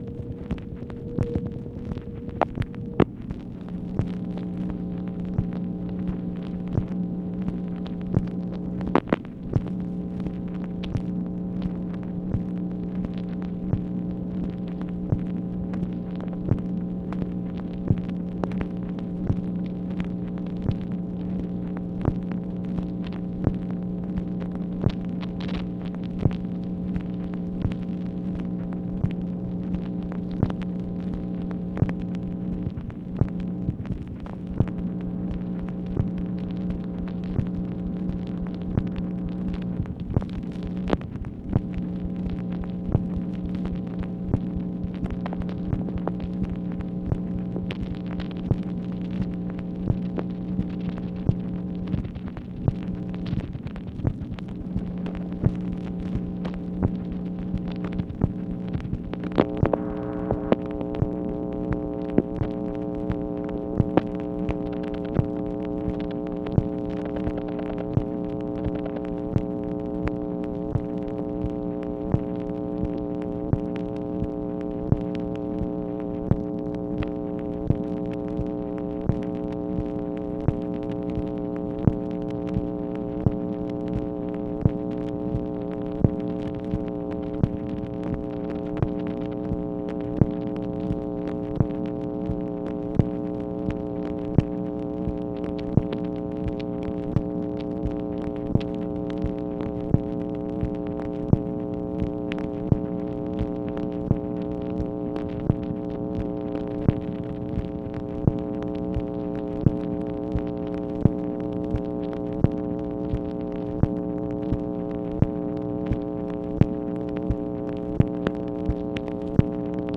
MACHINE NOISE, April 30, 1965
Secret White House Tapes | Lyndon B. Johnson Presidency